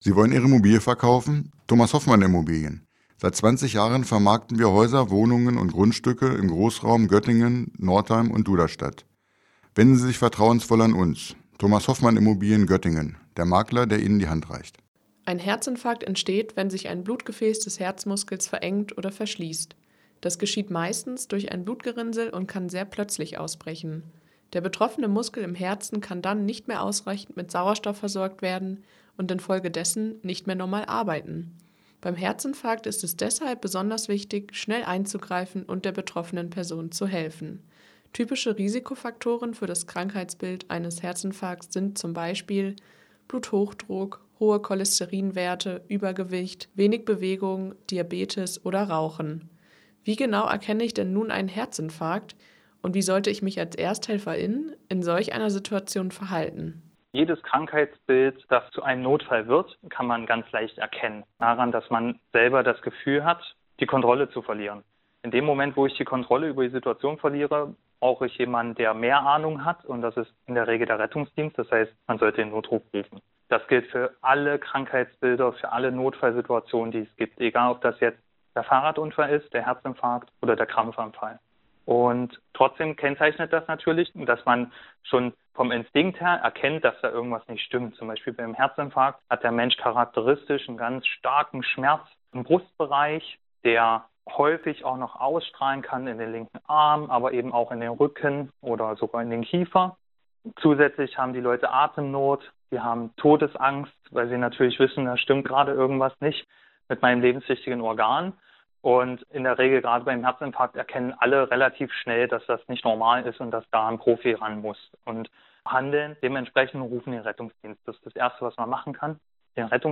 In der heutigen Ausgabe sprechen die beiden über typische Symptome und Verläufe des Herzinfarkts.